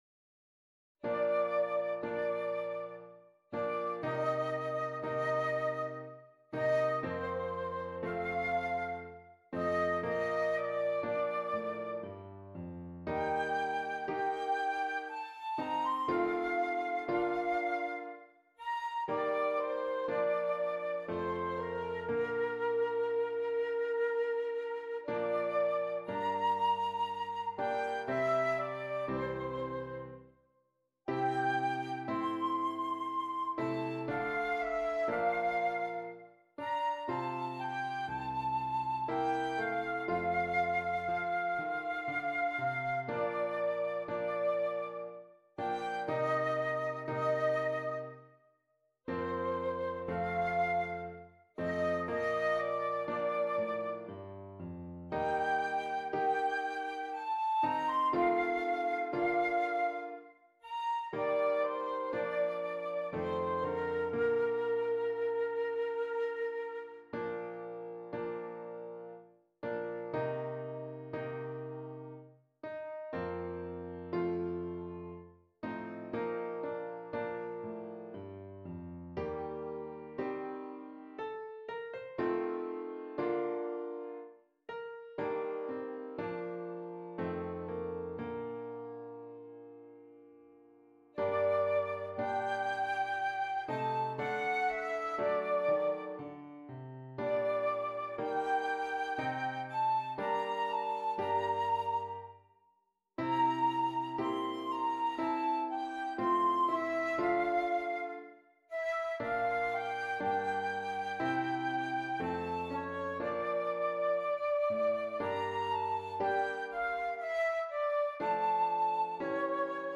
Flute and Keyboard